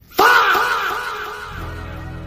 fahh but louder Meme Sound Effect
fahh but louder.mp3